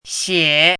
chinese-voice - 汉字语音库
xie3.mp3